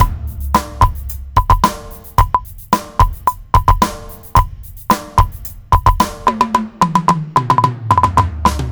(fs, x) = read('../audio/80sPopDrums.wav')
We can use librosa.clicks to check how we are doing (click to see detail)